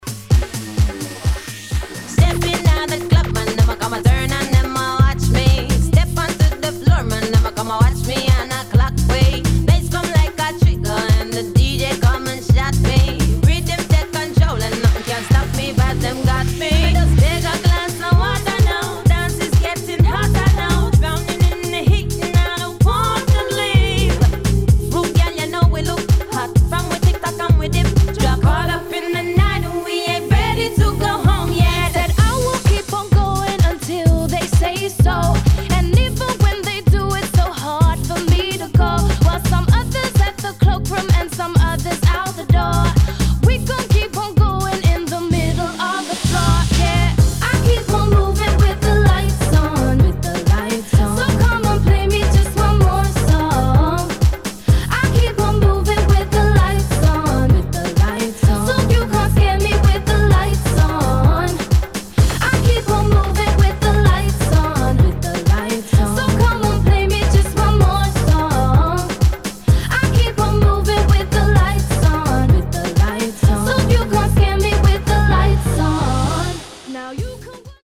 [ DUBSTEP / UK GARAGE ]